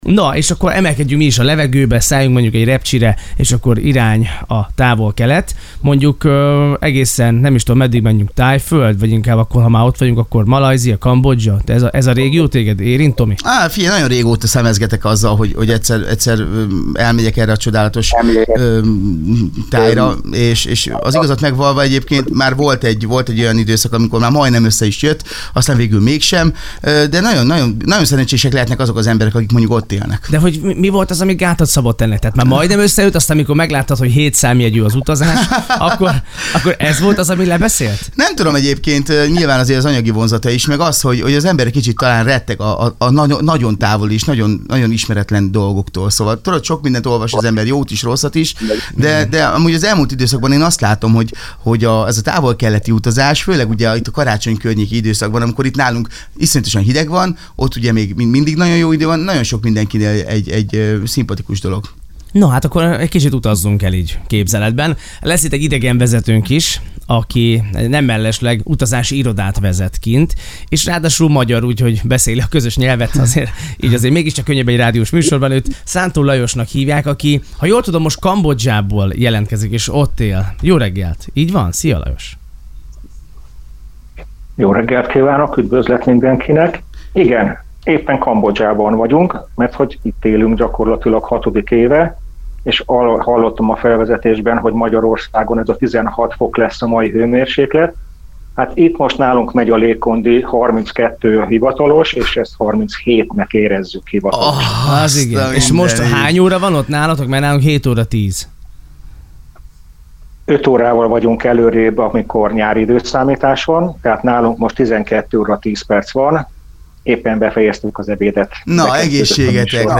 Rádió Interjú 99.4 Sunshine FM
Meghívást kaptunk a 99.4 Sunshine FM rádió reggeli Keltető műsorába egy könnyed beszélgetésre: